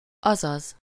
Ääntäminen
Ääntäminen RP : IPA : /ˌaɪ.ˈiː/ US : IPA : /ˌaɪ.ˈiː/ Haettu sana löytyi näillä lähdekielillä: englanti Käännös Ääninäyte 1. azaz Määritelmät (initialism) That is; in other words; that is to say.